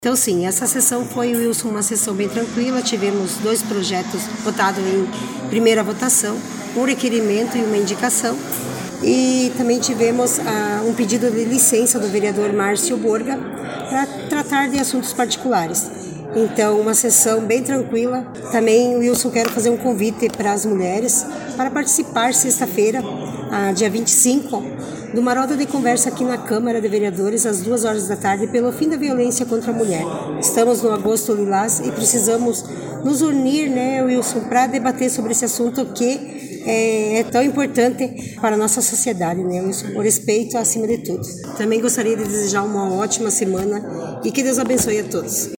Salete Silva Franciosi Presidente da Câmara comentou sobre as Indicações e Projetos aprovados.